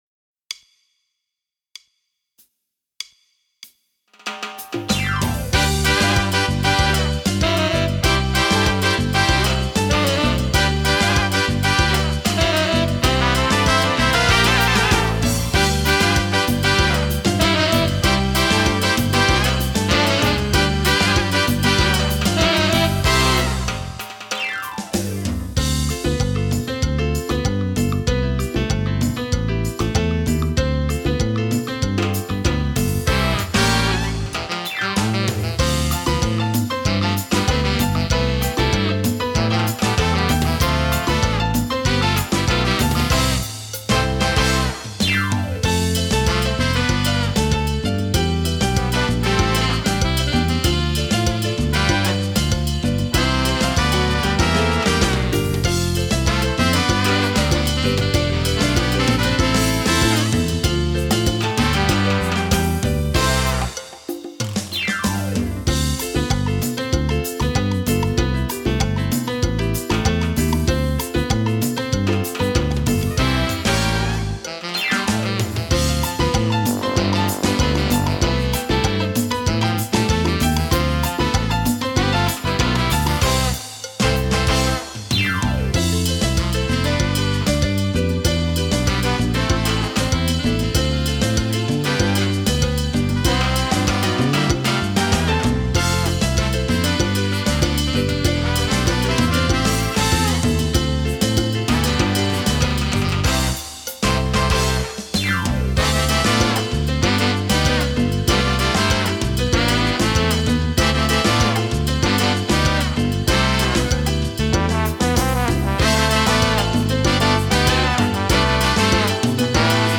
Mambo salsa
Sax / Orch.